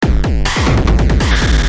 描述：硬碰硬的突击拍子
Tag: 140 bpm Glitch Loops Drum Loops 295.55 KB wav Key : Unknown